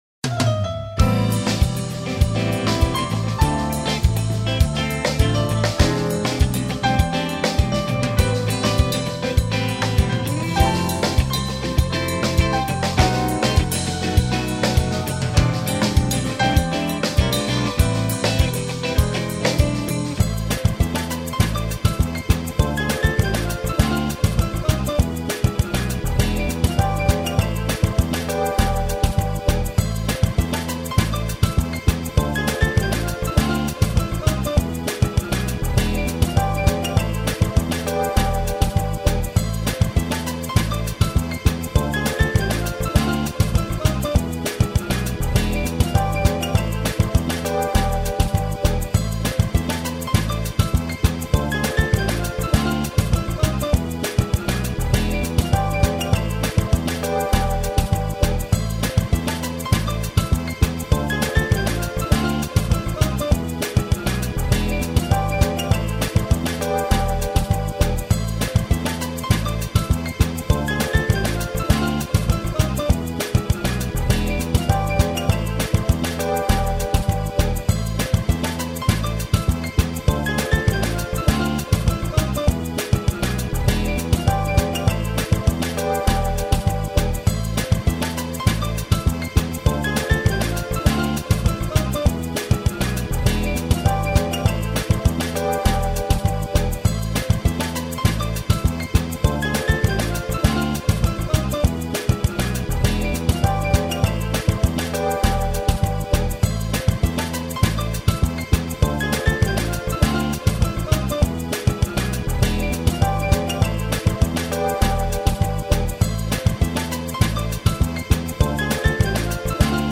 минусовка версия 244806